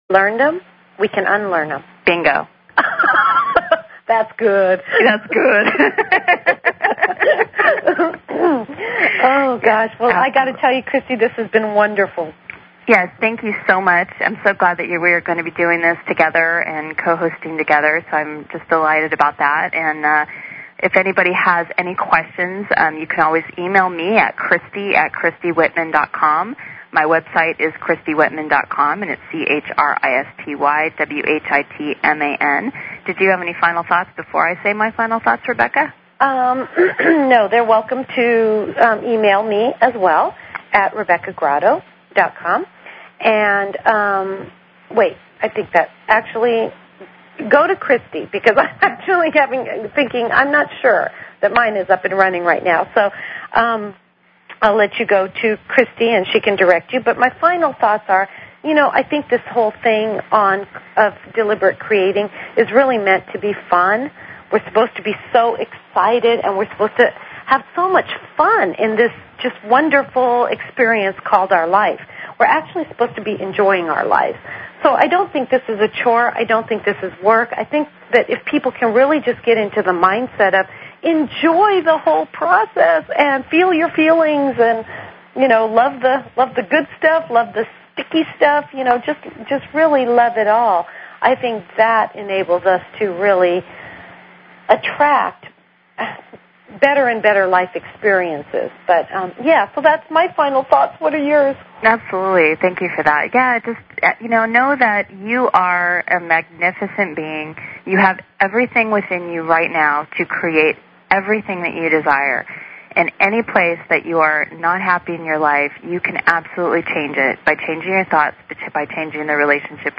Talk Show Episode, Audio Podcast, Hypnotized and Courtesy of BBS Radio on , show guests , about , categorized as